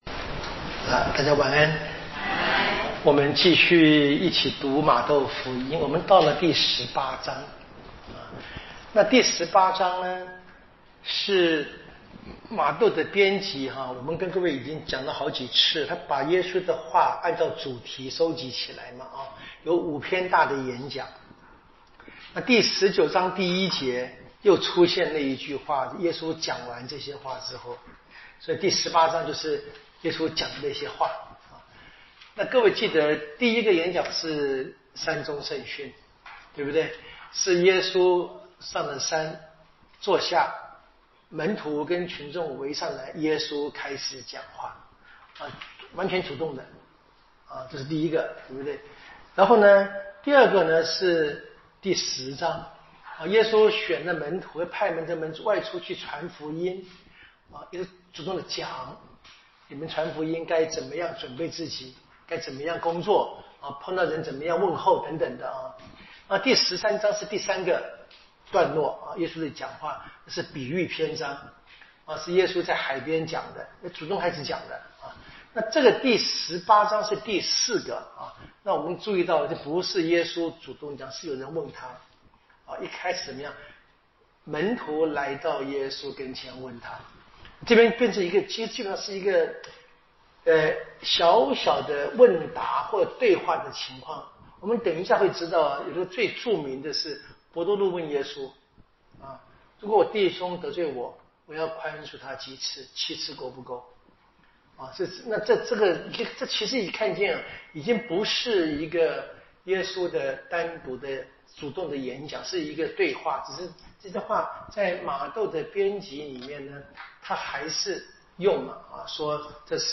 【圣经讲座】《玛窦福音》